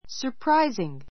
surprising A2 sə r práiziŋ サ プ ラ イズィン ぐ 形容詞 驚 おどろ くべき, 意外な, すばらしい surprising news surprising news 驚くべきニュース It is surprising that she can speak so many languages.